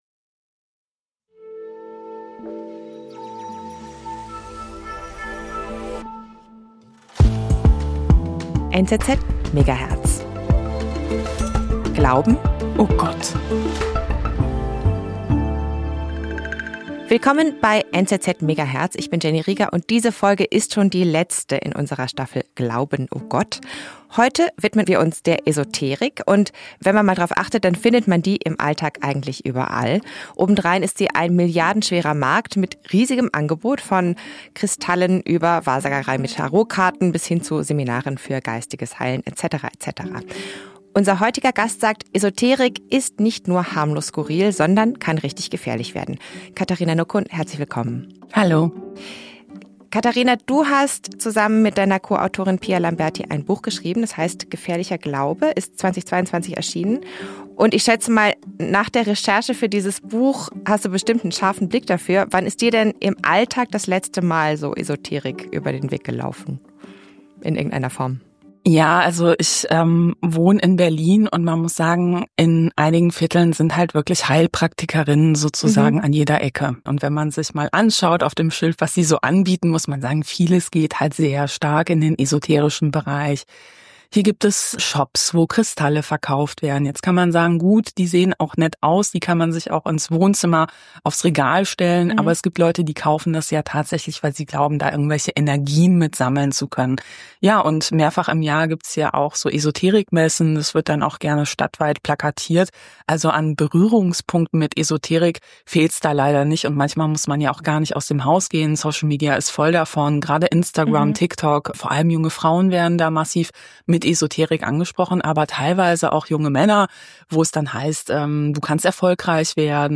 Unsere Hosts fragen sich das auch und sprechen mit Menschen, die Antworten gefunden haben. Jede Staffel nehmen wir uns ein grosses Thema vor und decken überraschende Fakten und Perspektiven auf.
Interview